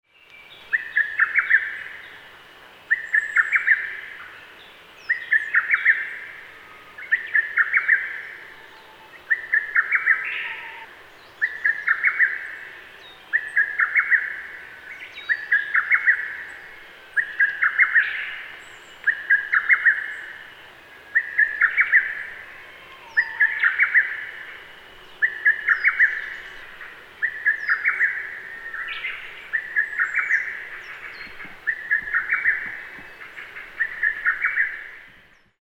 hototogisu_s2.mp3